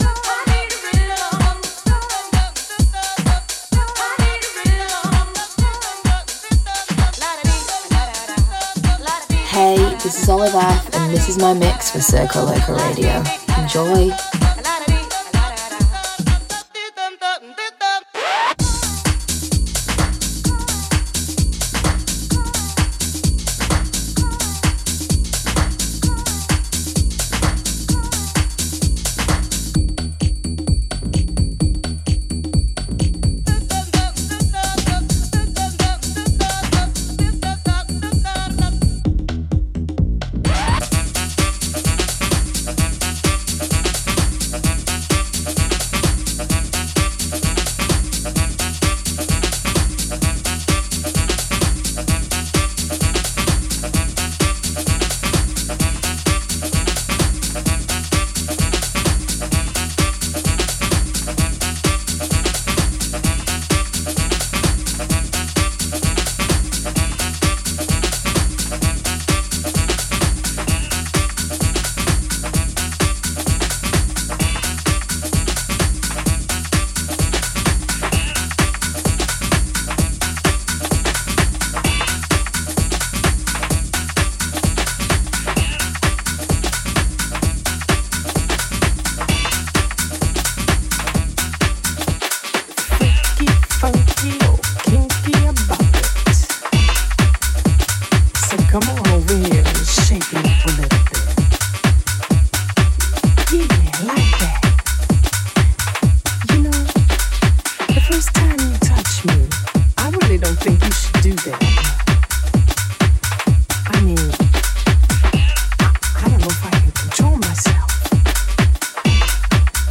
Bringing you new mixes from the best DJs in the world.